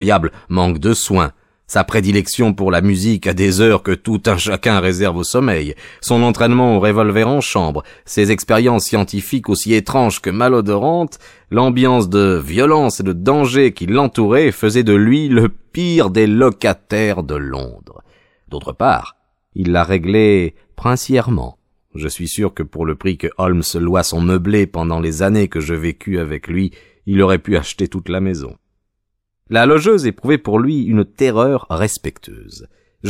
Diffusion distribution ebook et livre audio - Catalogue livres numériques
Cette adaptation audio est faite à la manière des grands raconteurs d'histoires, qui font revivre tous les personnages en les interprétant brillamment.